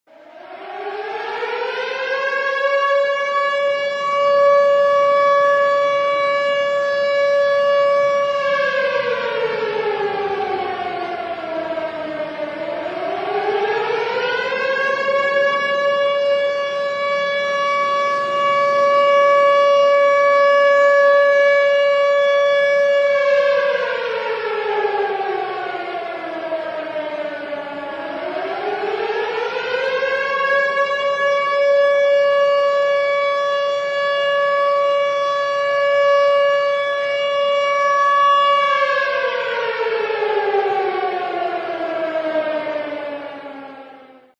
Звуки счетчика Гейгера
Вы можете слушать и скачивать характерные щелчки и трески, имитирующие обнаружение радиации.
Короткий щелчок Гейгера